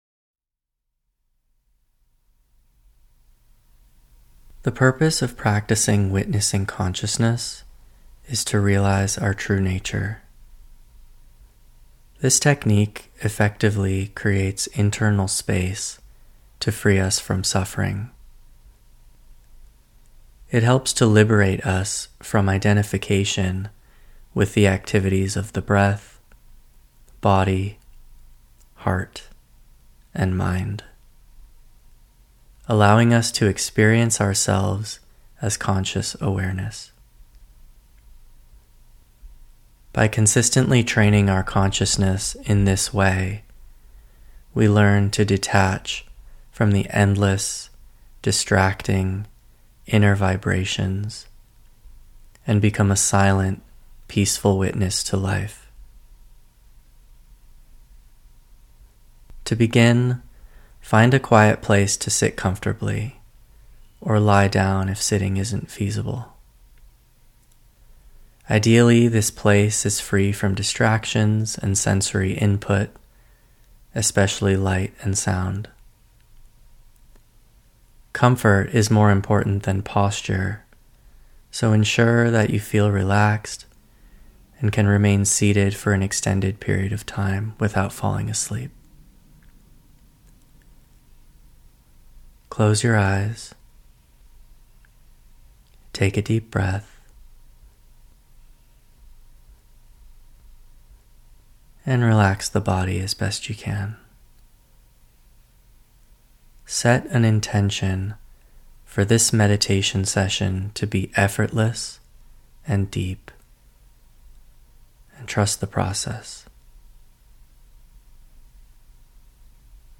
Witnessing-Consciousness-Meditation.mp3